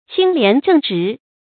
清廉正直 qīng lián zhèng zhí
清廉正直发音